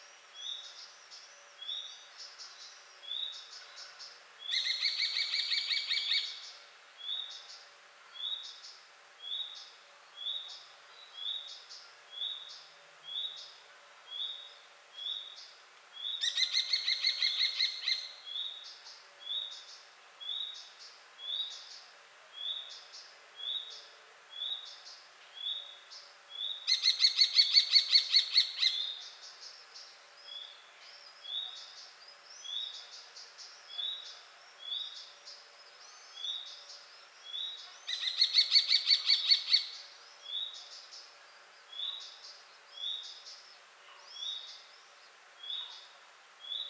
Vörös vércse (Falco tinnunculus) hangja
A vörös vércse (Falco tinnunculus) hangja alapvetően magas, éles és jellegzetesen sziszegő. A madár főként rövid, ismétlődő „kik-kik-kik” hangokat ad ki, amelyek könnyen felismerhetők a természetben. Ezek a hangok gyakran gyors, szaggatott sorozatokban jelentkeznek, és a madarak különböző helyzetekben használják őket. A hangmagasság általában 3–5 kHz körül mozog, ami az emberi fül számára elég élesnek tűnik.
A vörös vércse (Falco tinnunculus) hangja jellegzetes, magas frekvenciájú és ismétlődő kiáltásokból áll, amely egyszerre szolgál kommunikációs, territoriális és riasztó célt.